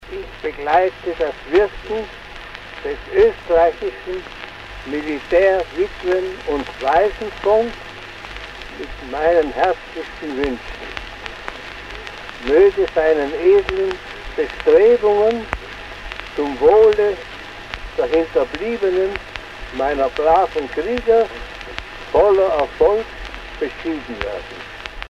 Kaiser Franz Josef I. im Originalton
Aus einem Mitschnitt einer Radiosendung vor über 30 Jahren.